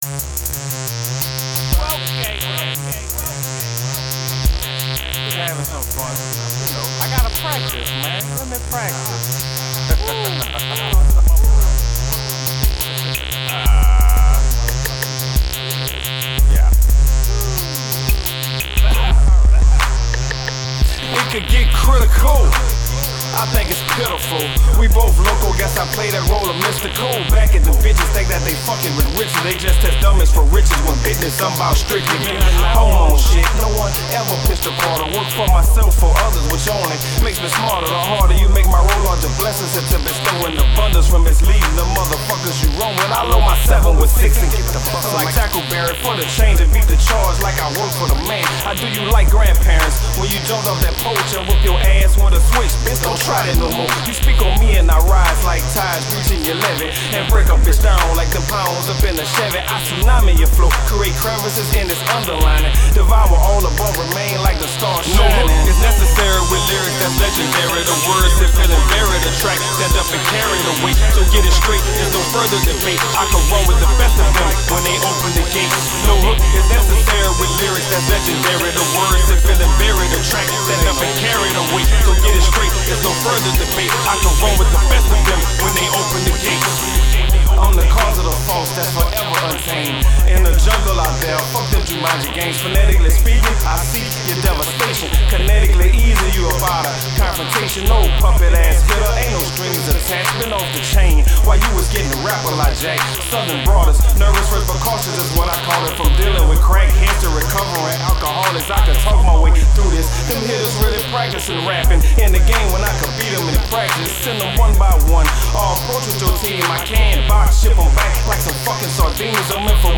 Hiphop
engages in "vanity" rap.